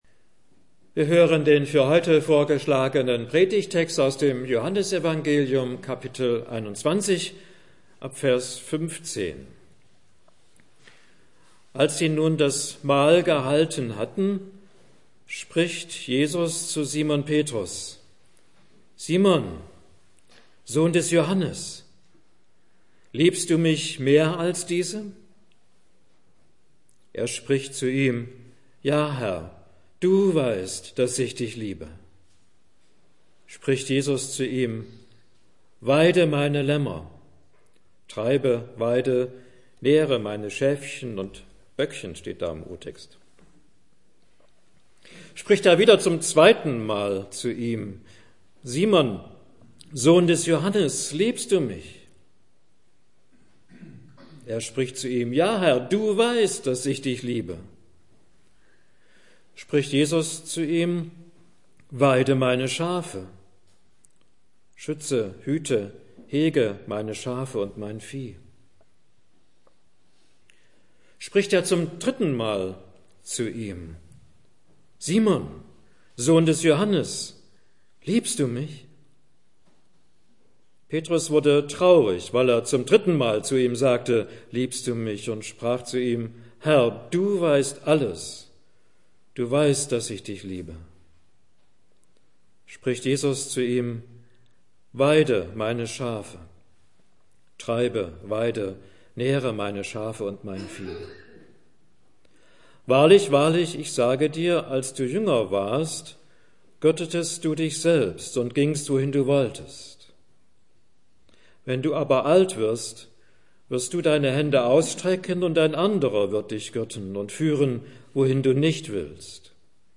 Mai 2015 Heruntergeladen 365 Mal Kategorie Audiodateien Predigten Schlagwörter Nachfolge , Jesus , Liebe , Petrus , vergebung , johannes 21 Beschreibung: Tischgespräch mit Jesus